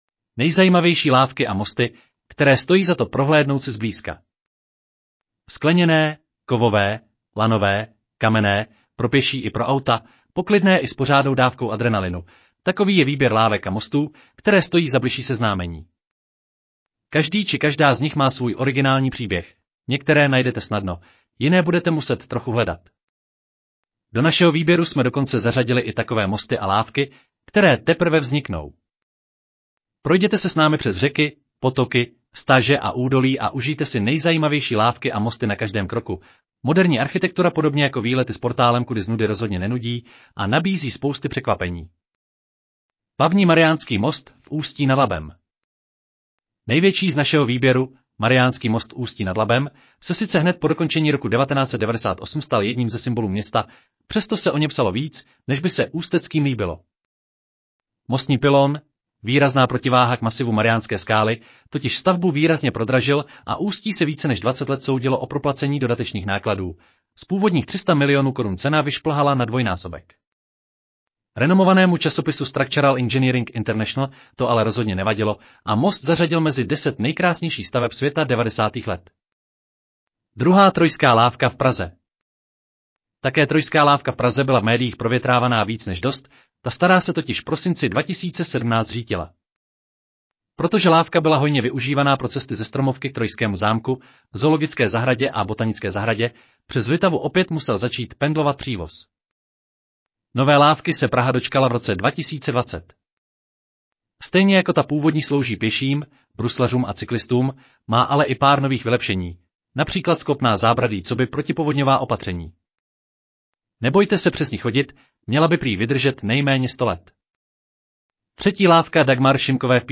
Audio verze článku Nejzajímavější lávky a mosty, které stojí za to prohlédnout si zblízka